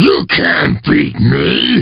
Line of Wizpig in Diddy Kong Racing.